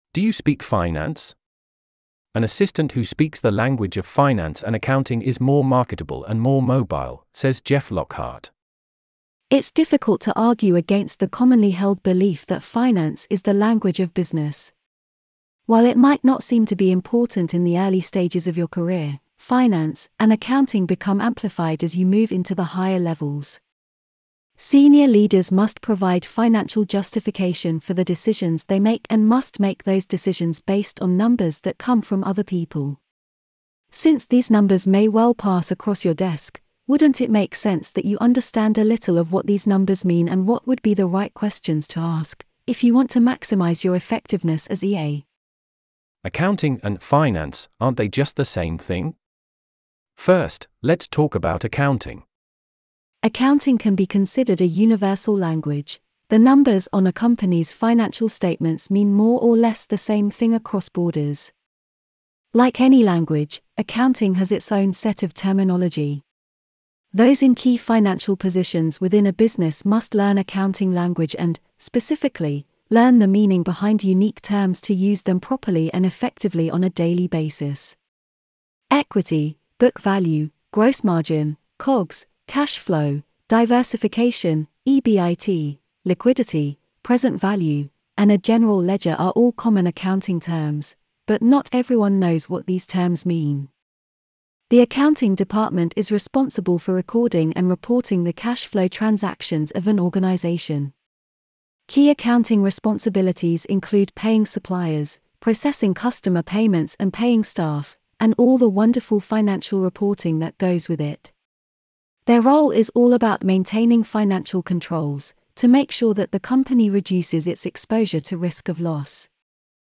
Do you speak Finance Audio (powered by Reedz AI - MP3)